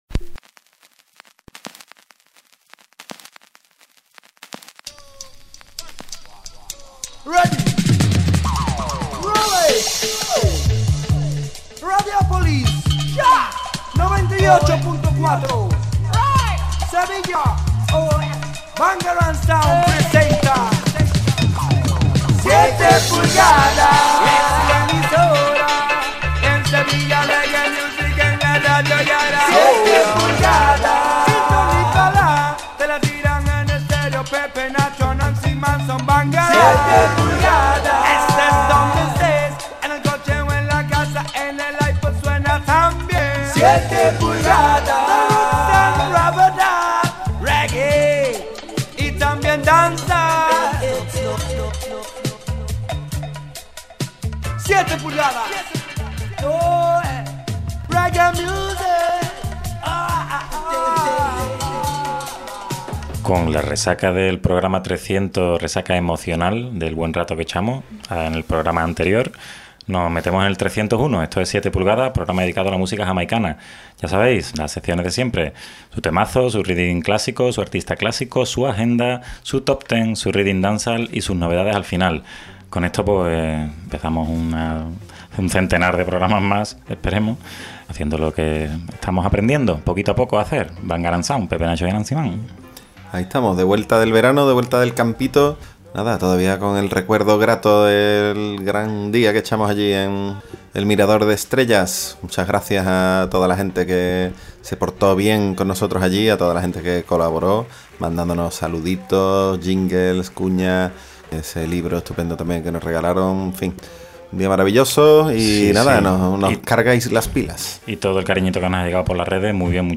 7 PULGADAS Reggae Radioshow: Programa 301